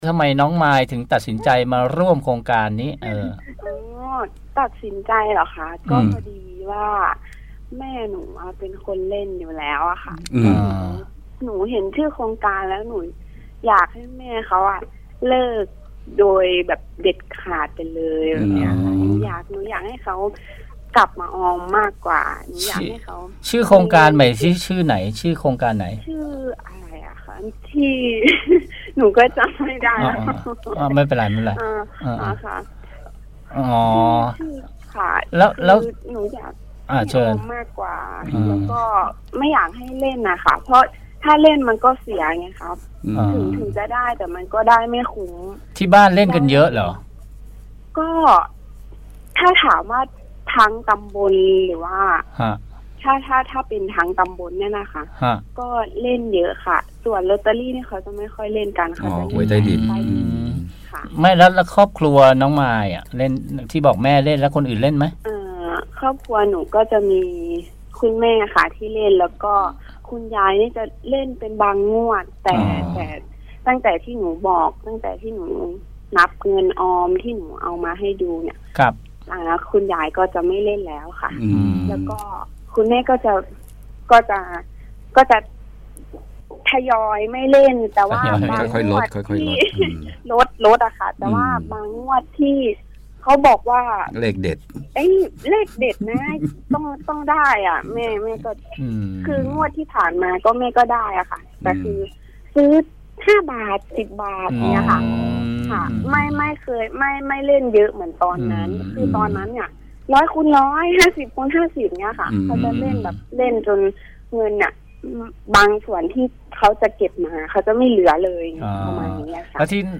สัมภาษณ์